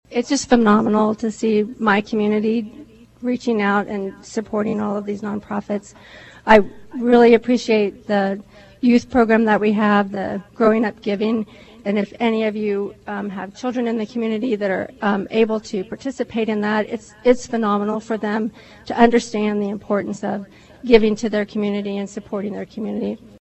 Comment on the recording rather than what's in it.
There were signs of a massive Match Day before Tuesday’s official check presentation ceremony — but most people on hand were totally shocked at the individual check amounts and the final total announced at Flinthills Mall as part of live coverage on KVOE.